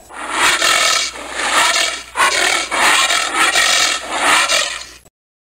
Звуки муравьеда
Грозное рычание муравьеда